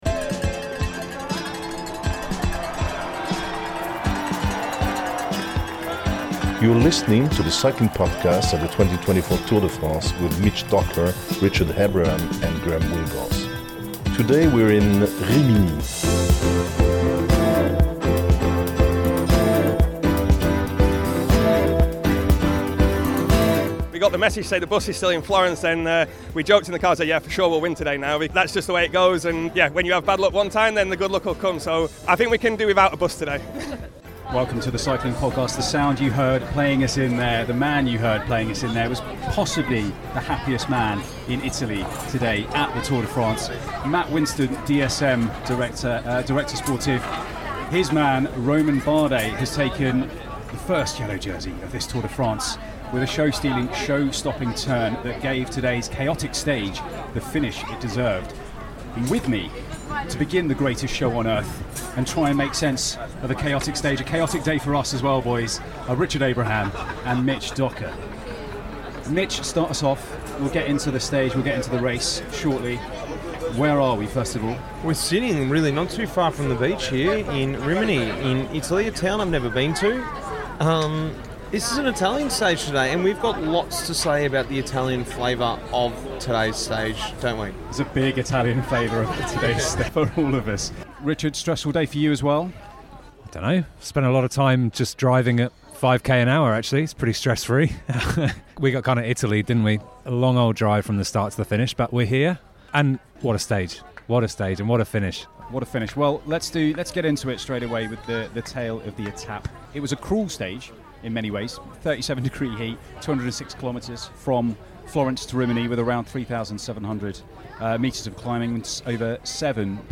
With a new line-up for the 2024 Tour, there’ll be the familiar mix of lively discussion, race analysis, interviews from Outside the Team Bus and some French flavour.